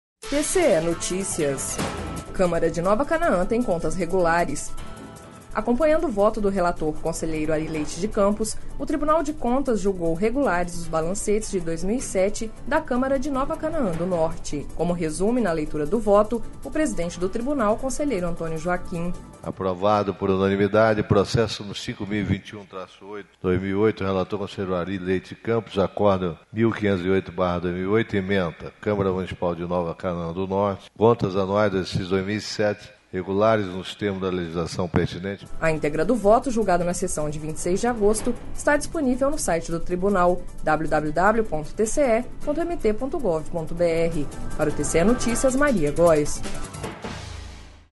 Acompanhando o voto do relator conselheiro Ary Leite de Campos, o Tribunal de contas julgou regulares os balancetes de 2007 da Câmara de Nova Canãa do Norte./ Como resume na leitura do voto o presidente do Tribunal, conselheiro Antonio Joaquim.// Sonora: Antonio Joaquim - presidente do TCE-MT